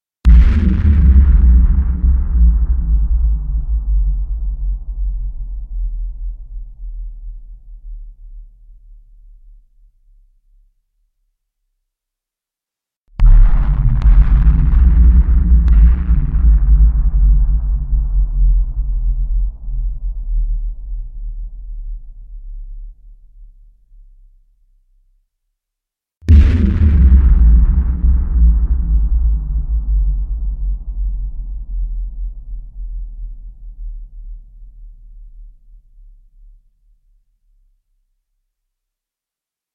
Звуки великана
Здесь собраны впечатляющие аудиоэффекты: гулкие шаги, низкие рыки, скрип древних деревьев под тяжестью гигантов.
1. Звук падения великана на землю n2. Грохот, где великан рухнул на землю n3. Шум, когда великан упал на землю n4. Звук, как великан свалился на землю n5. Гул от падения великана на землю